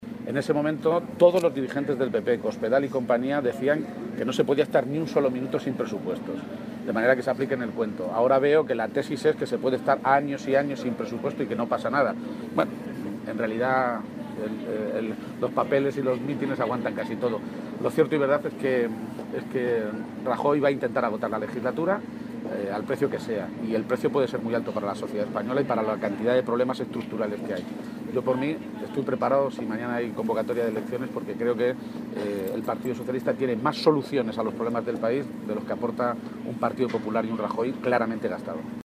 Así lo reconocía a su llegada al Comité Federal del PSOE celebrado esta mañana en Aranjuez.
Cortes de audio de la rueda de prensa